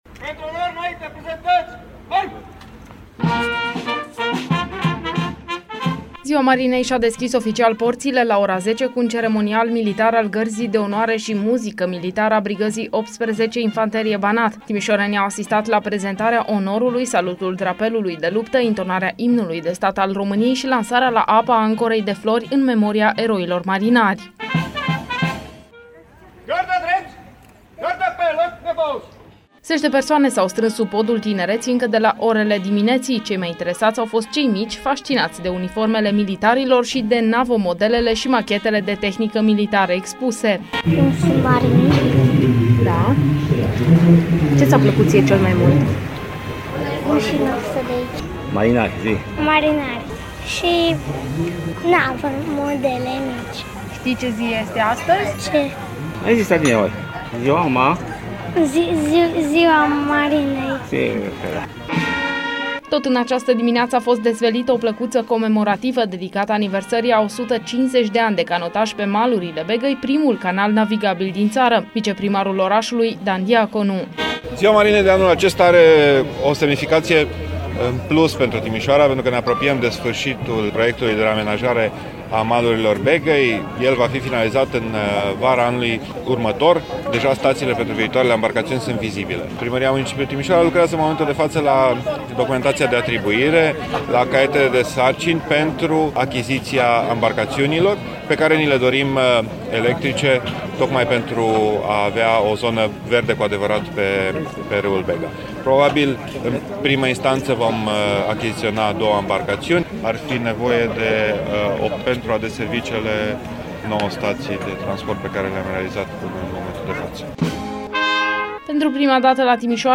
Un reportaj de Ziua Marinei Române a realizat pentru Radio Timişoara.